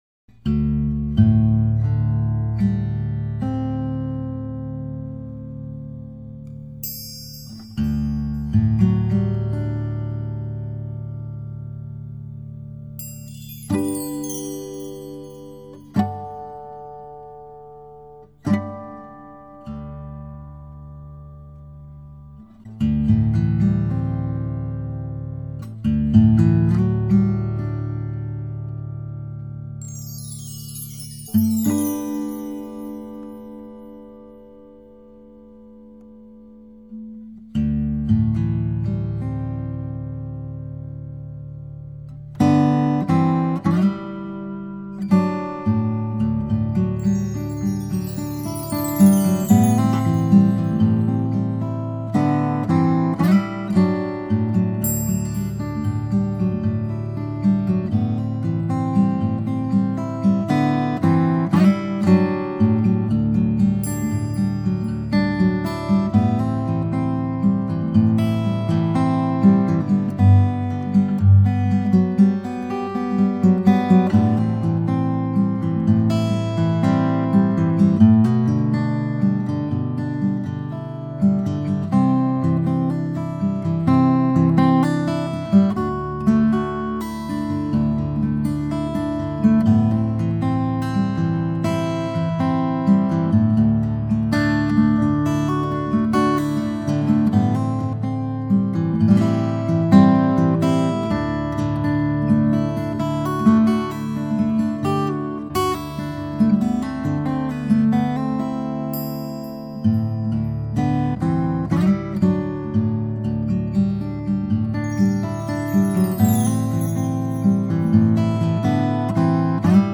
Carruth 000 12-C
To hear the guitar in action, click here--   A Mighty Fortress    and